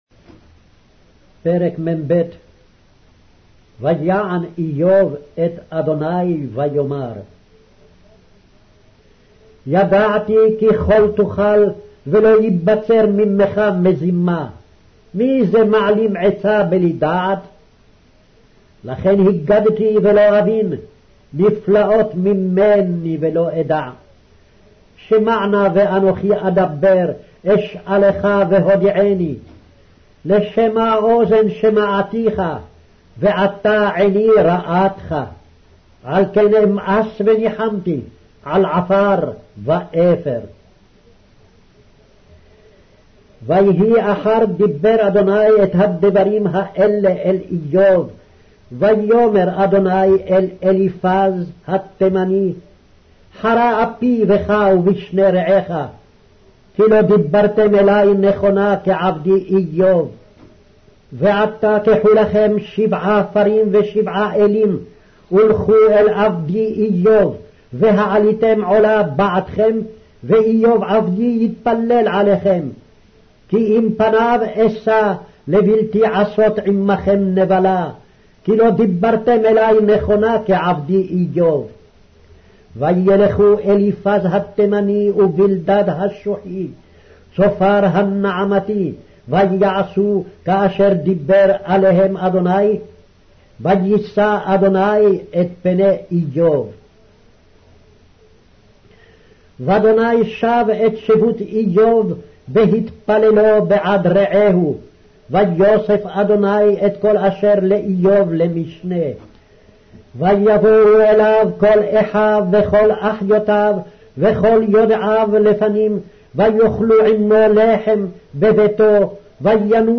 Hebrew Audio Bible - Job 17 in Ervbn bible version